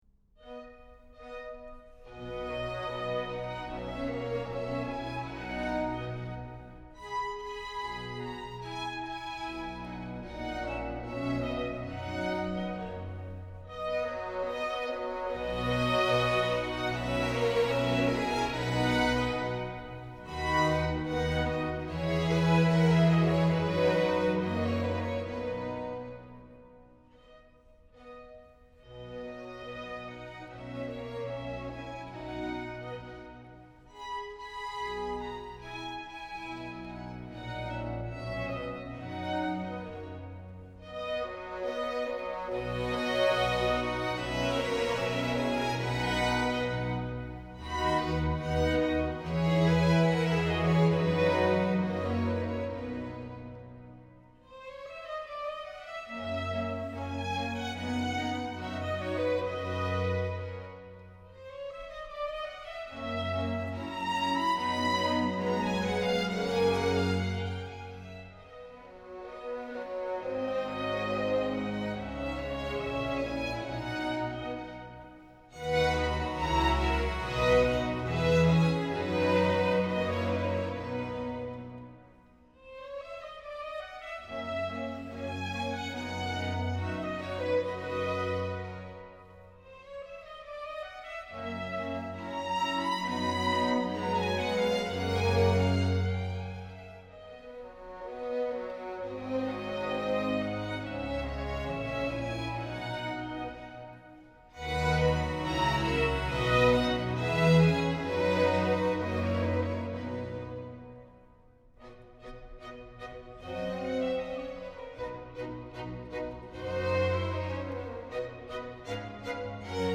As a result, a serenade is usually lighter or calmer music.
2. Romanze: Andante (romance; a slower walking speed)
For those interested, this recording was performed by The English Concert conducted by Andrew Manze.